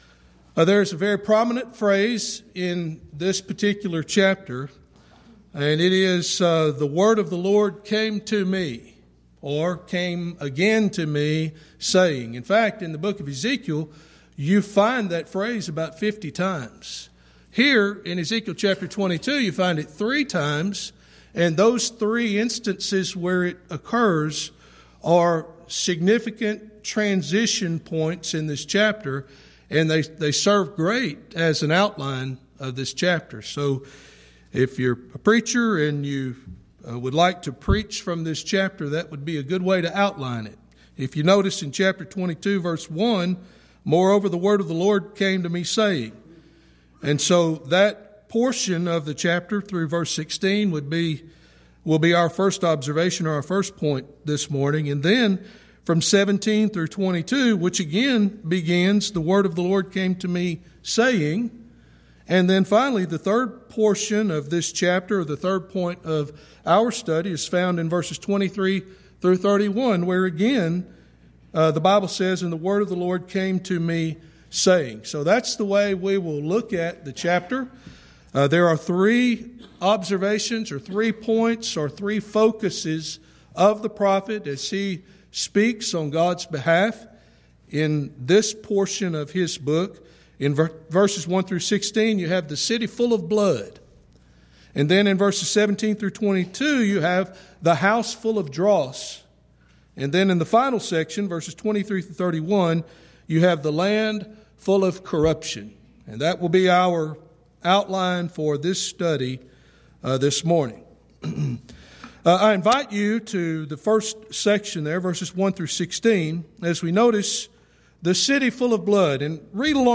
Event: 10th Annual Schertz Lectures
lecture